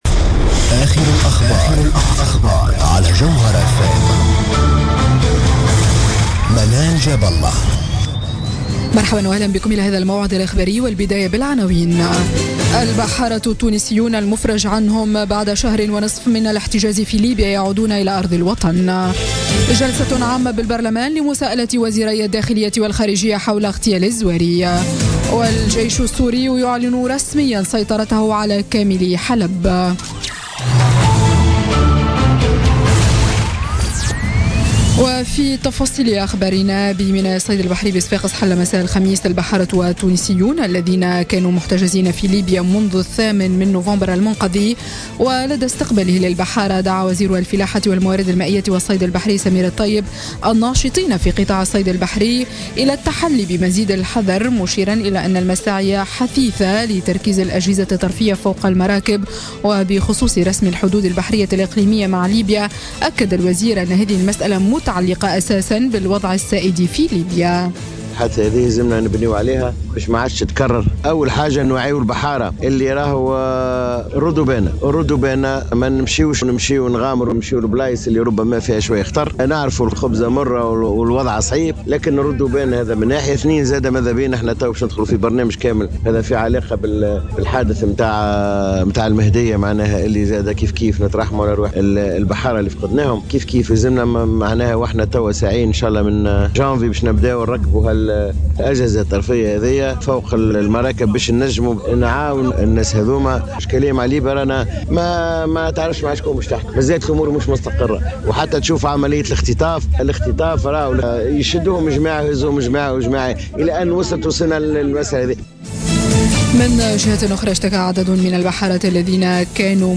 نشرة أخبار منتصف الليل ليوم الجمعة 23 ديسمبر 2016